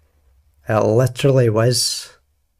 glaswegian
scottish